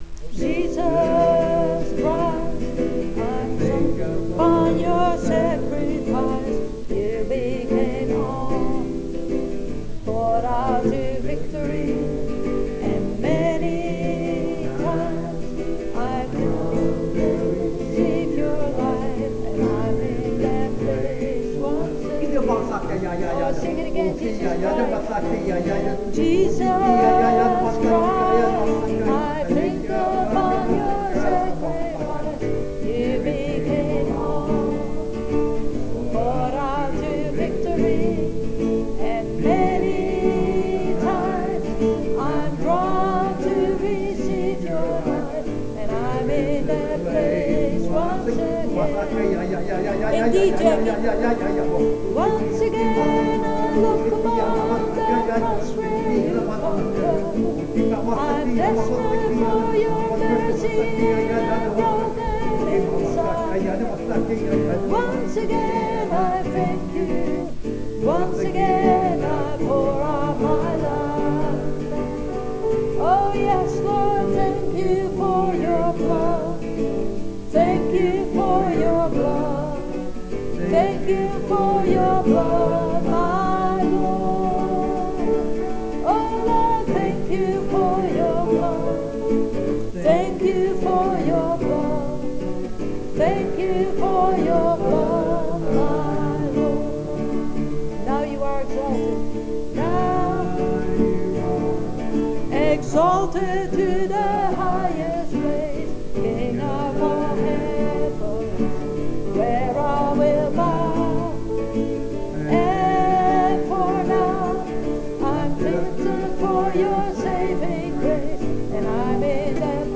Worship-8.wav